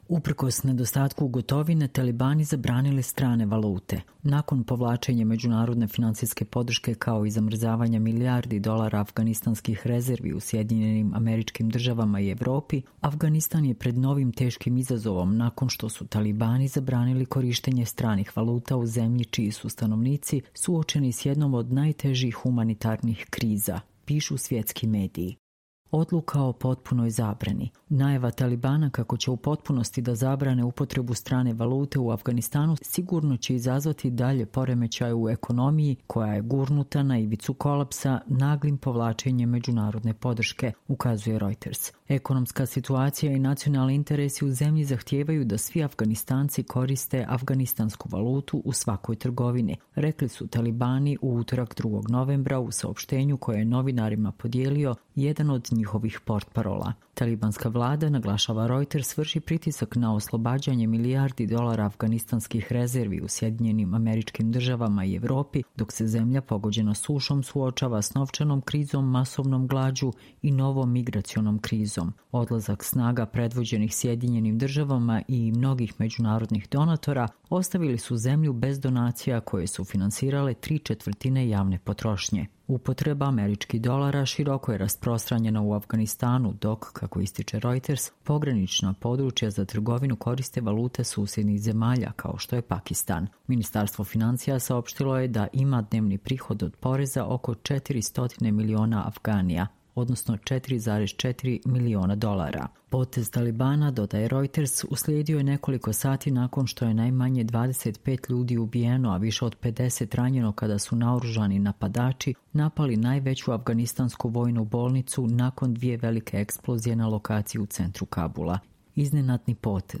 Čitamo vam: Uprkos nedostatku gotovine talibani zabranili strane valute